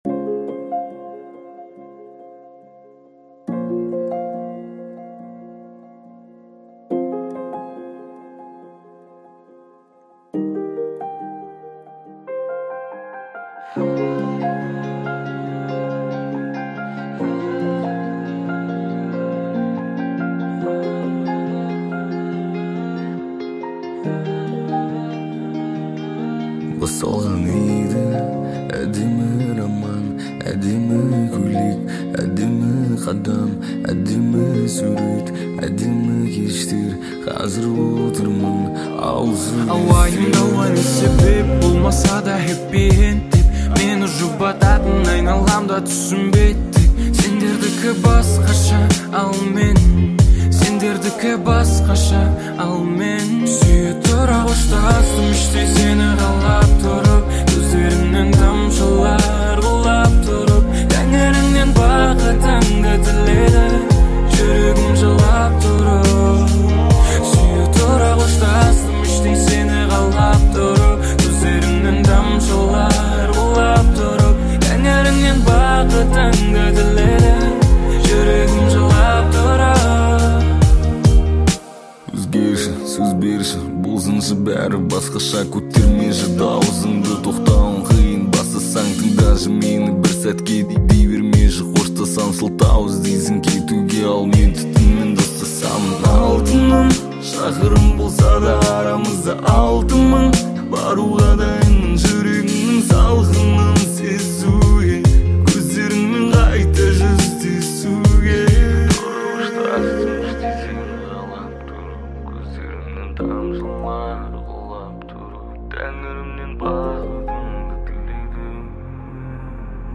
Жанр: Казахские песни